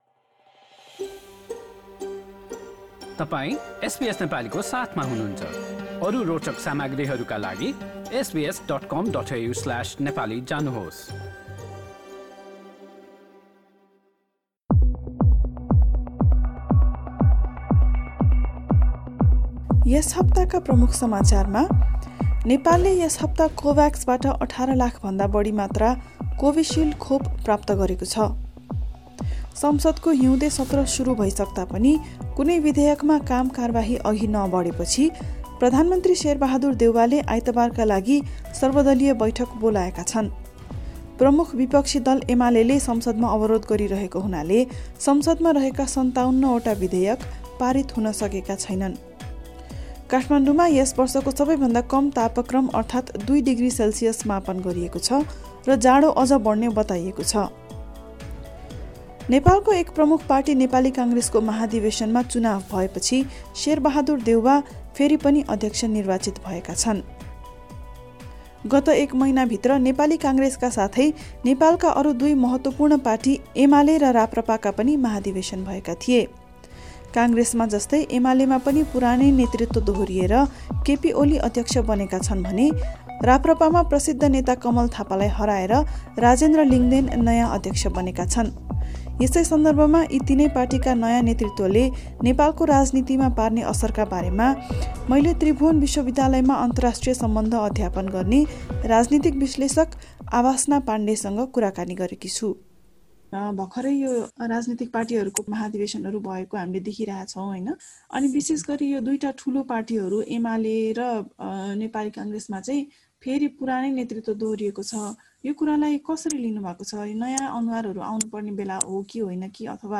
नेपालका गत सात दिनका प्रमुख समाचारका साथ प्रमुख राजनीतिक दलहरूको नेतृत्वमा पुरानै अनुहार दोहोरिँदा पार्टी भित्र प्रजातान्त्रिक अभ्यासको कमी रहेको प्रस्ट हुने एक विश्लेषकको भनाइ सहितको साप्ताहिक नेपाल सन्दर्भ।